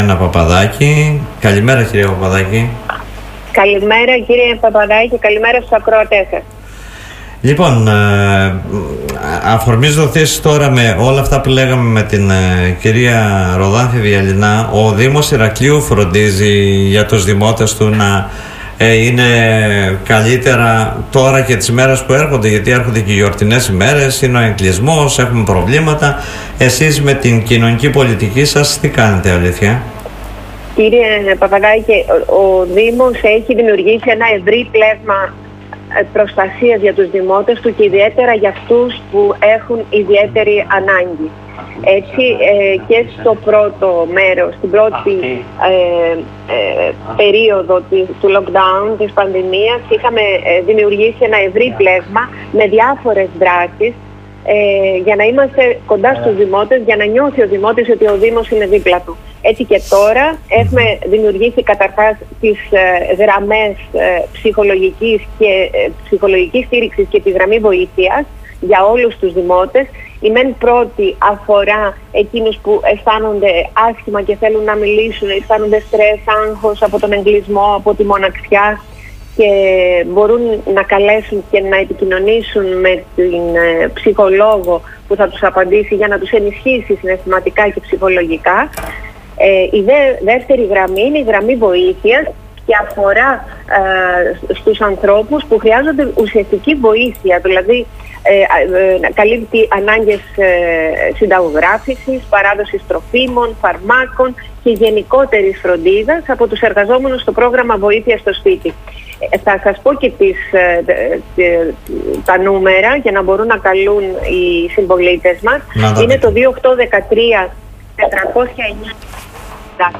Ο δήμος Ηρακλείου είναι κοντά στους πολίτες που έχουν ανάγκες επεσήμανε στον Politica 89.8